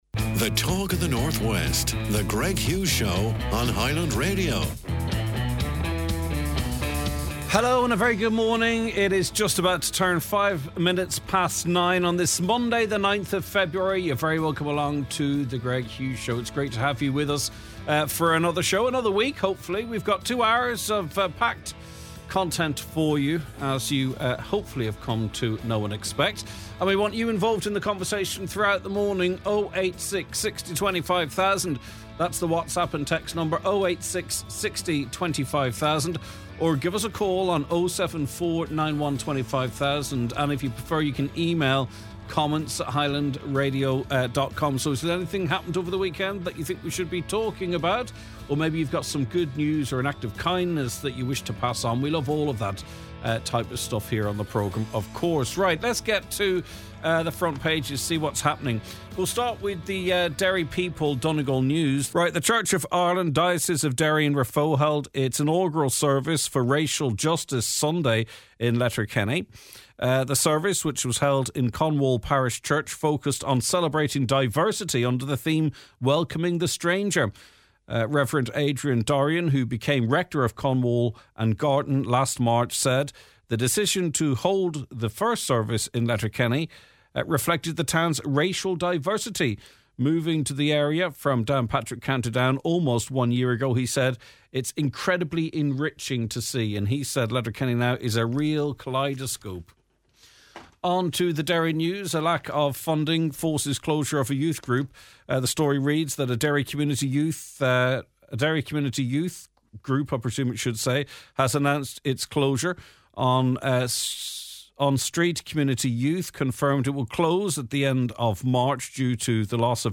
Protest at Bord Bia: We go live to the offices of Bord Bia where a protest is currently underway.
Listeners share their frustrations over the rise of video recording in pubs and at dances.